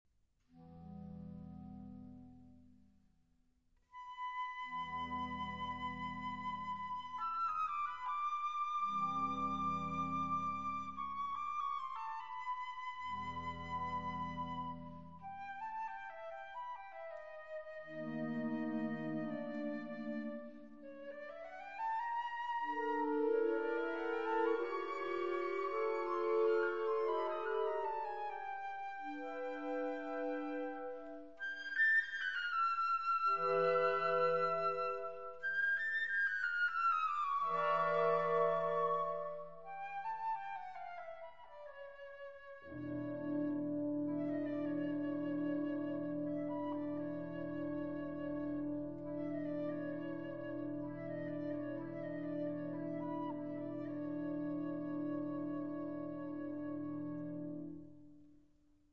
Categoría Banda sinfónica/brass band
Subcategoría Música contemporánea original (siglo XX y XXI)
Instrumentación/orquestación Ha (banda de música)